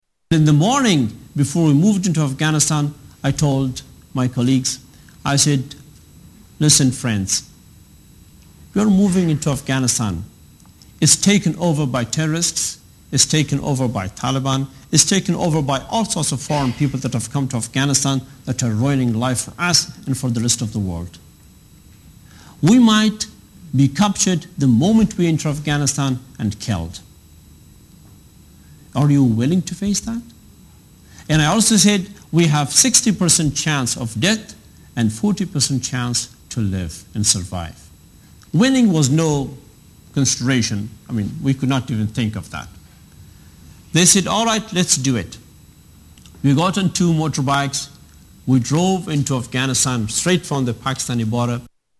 Tags: Political Hamid Karzai audio Interviews President Afghanistan Taliban